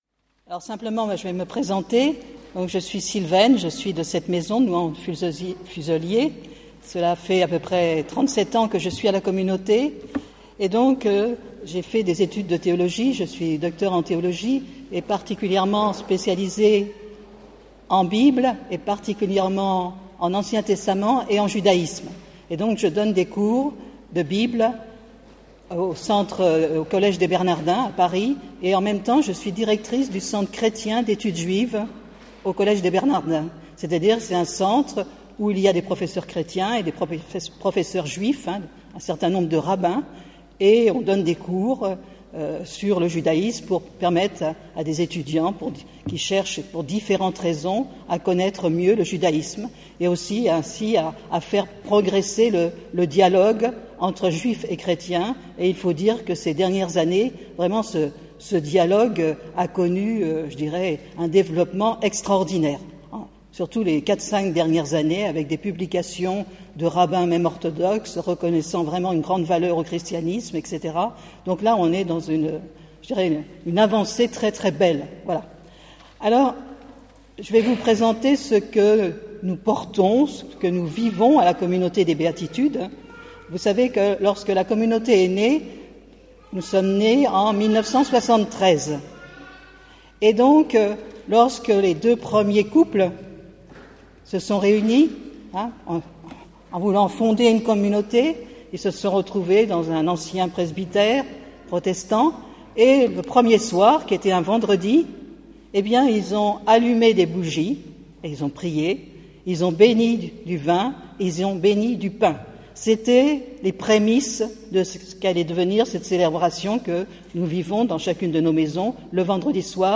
Vatican II nous enseigne que nous, chr�tiens, ne pouvons comprendre qui nous sommes sans conna�tre la tradition d'Isra�l, dont est issu J�sus. Or, garder le Shabbat est central et sp�cifique � Isra�l. Comment accueillir, en tant que non Juifs, cette b�n�diction destin�e � toute la cr�ation ? Enregistr� en 2017 (Fraternit� In Christo)Intervenant(s